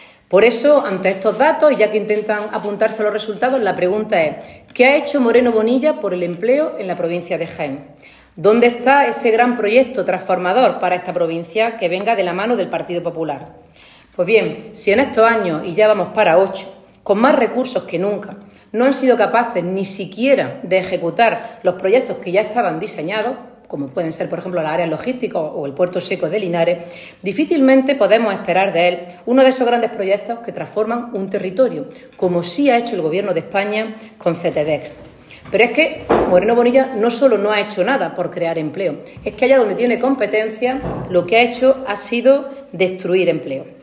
En rueda de prensa, Cobo destacó que las políticas laborales del Gobierno y el despliegue de los fondos Next Generation han permitido impulsar “un cambio de modelo productivo para adaptar la economía a la transformación tecnológica” que estamos viviendo.
Ana-Cobo-empleo-3.mp3